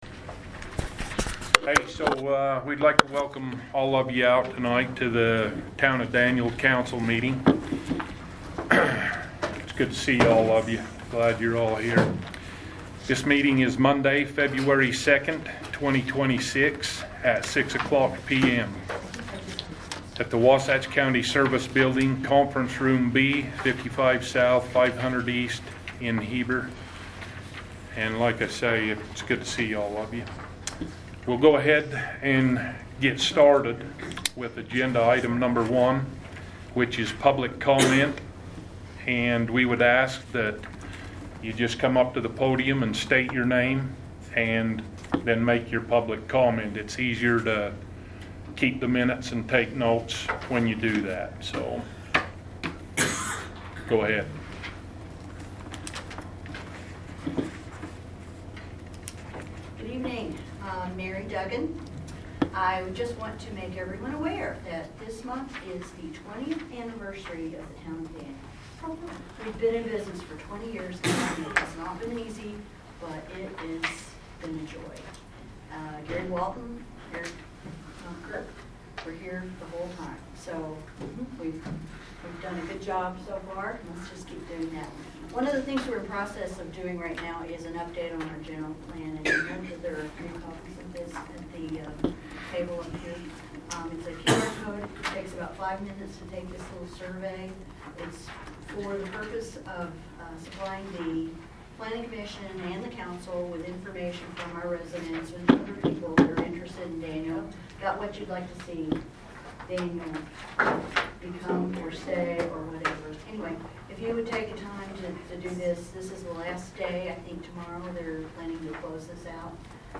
February 2, 2026 Town Council Audio Minutes